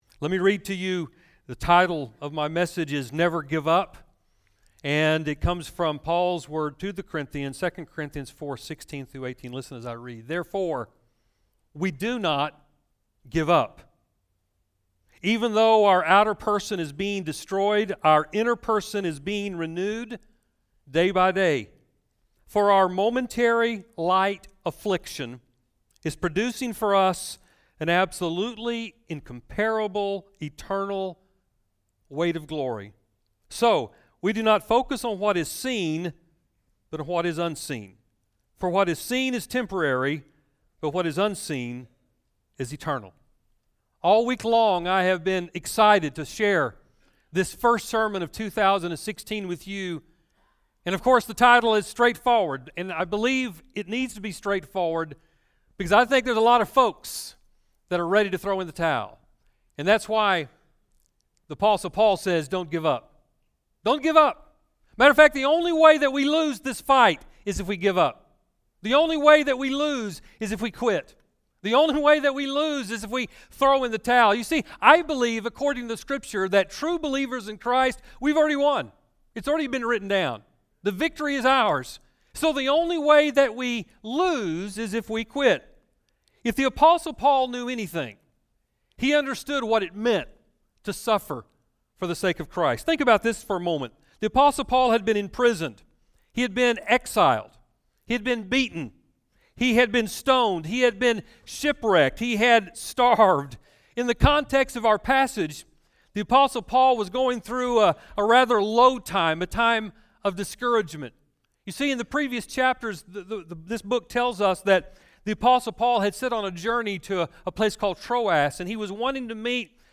A special New Years message as we kick off 2016.